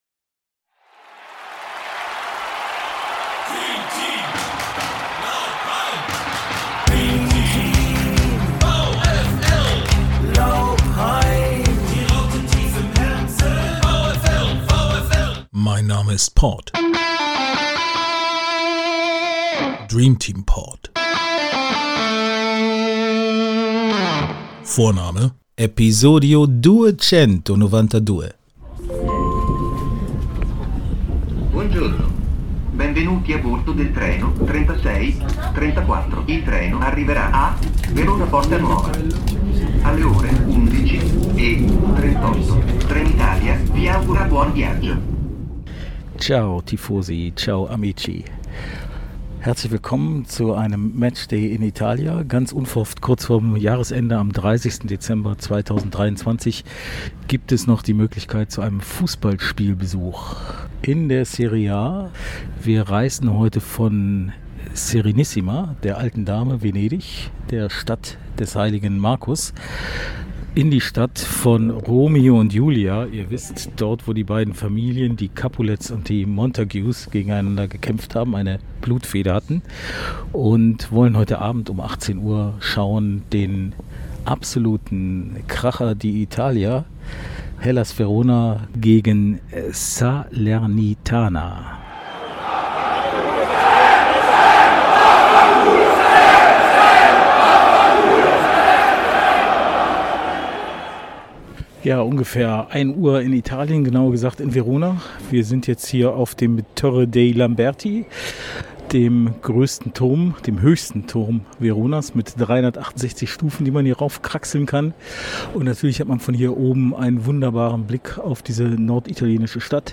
Und doch halten die Gäste - die granate , also die Granatroten - so einige Kracher für die giallo blu , die gelbblauen Veronesi bereit: da sind die verfrühten Silvesterböller, die in geradzu polnischer Lautstärke markerschütternd die schwarzpulververarbeitende Handwerkskunst der Süditaliener bezeugen.